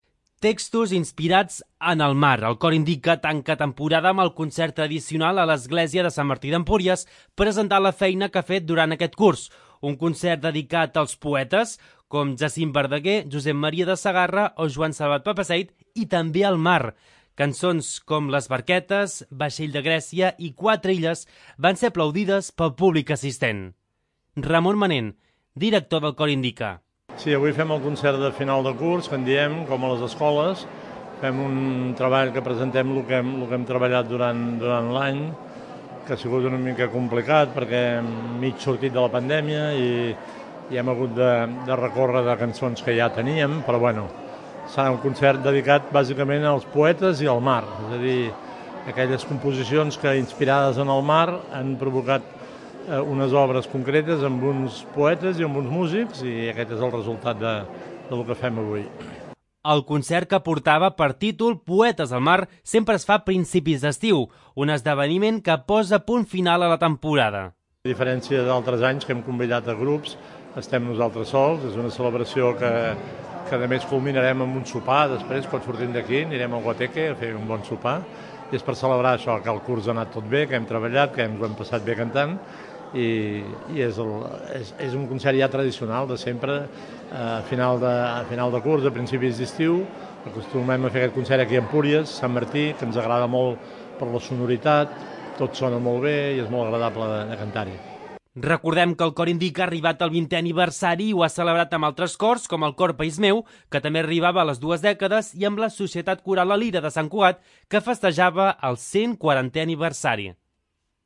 El Cor Indika tanca el curs presentant textos inspirats en el mar a l'església de Sant Martí d'Empúries. És el concert tradicional que fa la coral escalenca a principis d'estiu per cloure temporada.